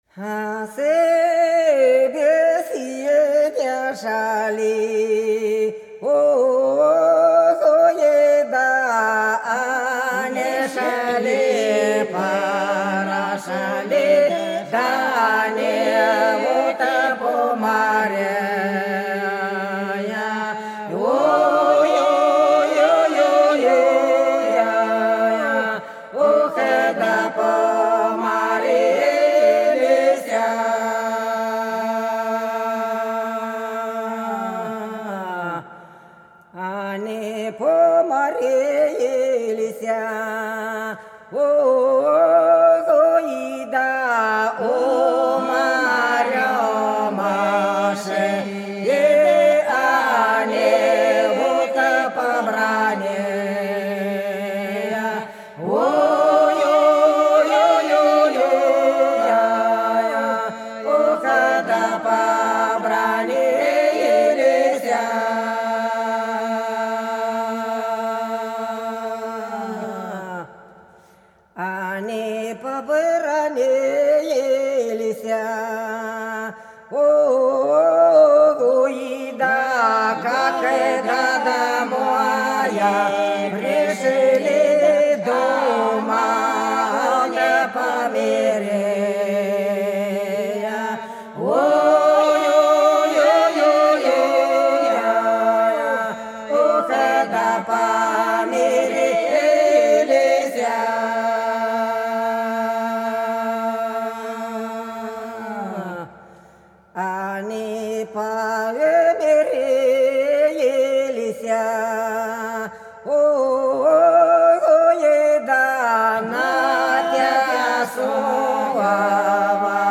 Белгородские поля (Поют народные исполнители села Прудки Красногвардейского района Белгородской области) У нас со беседы шли - протяжная